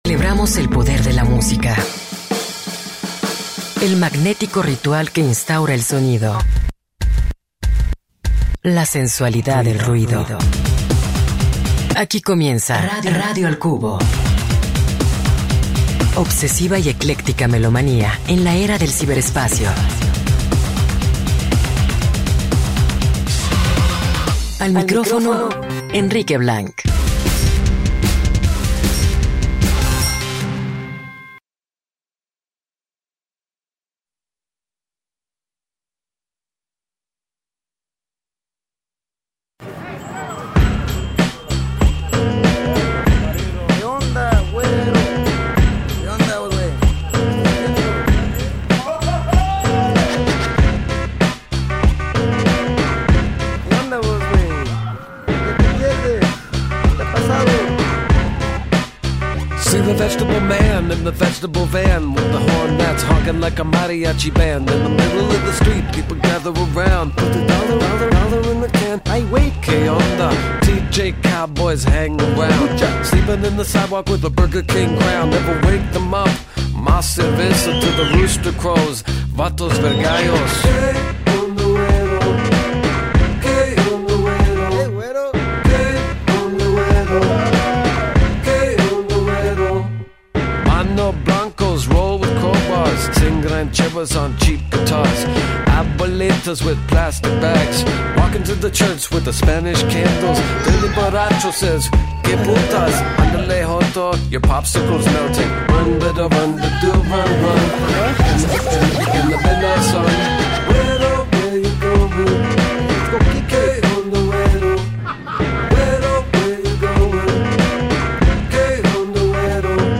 Escucha la entrevista en el 104.3FM